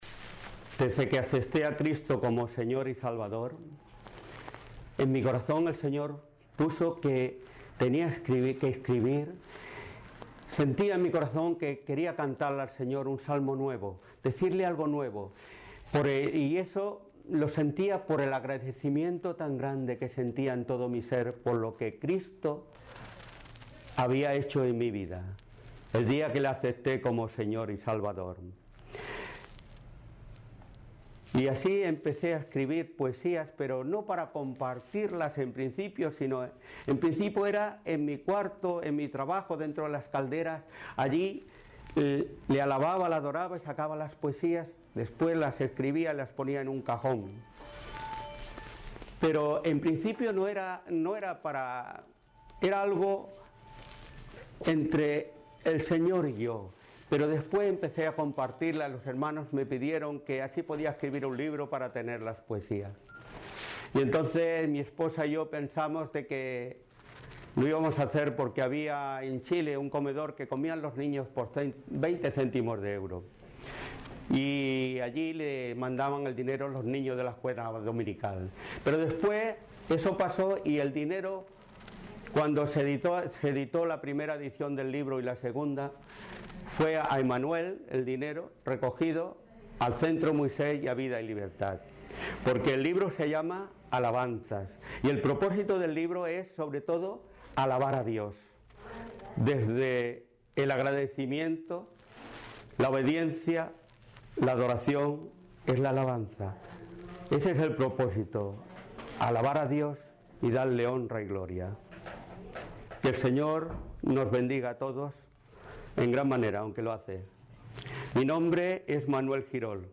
Poesía en Audio